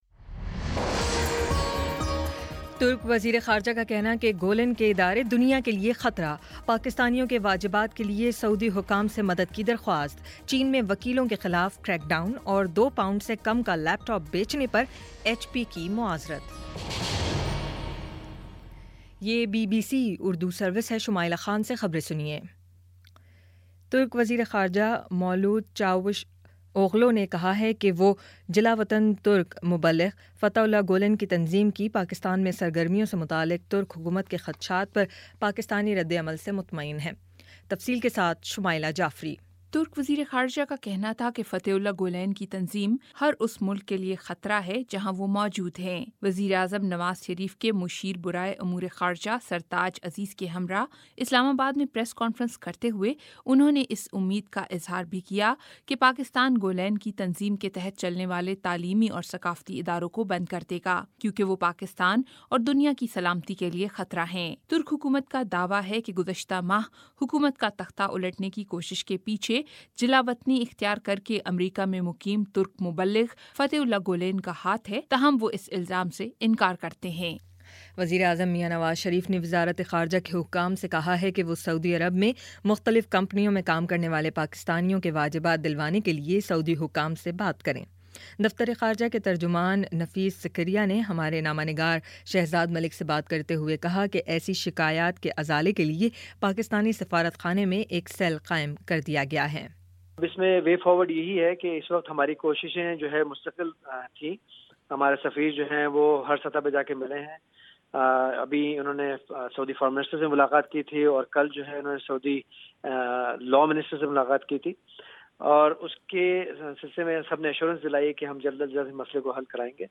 اگست 02 : شام پانچ بجے کا نیوز بُلیٹن